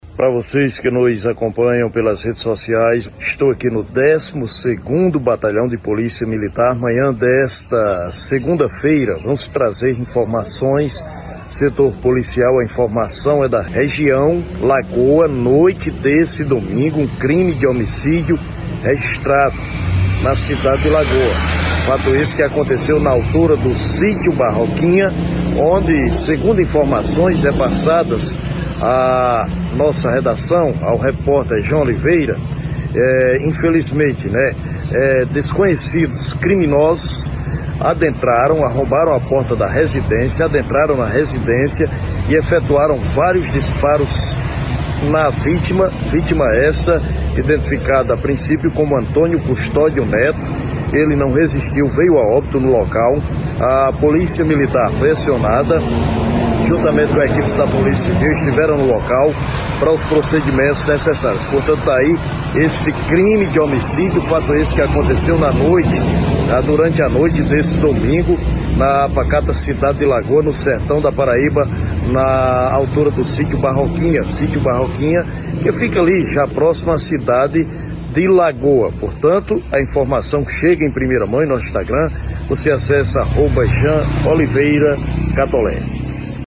Policial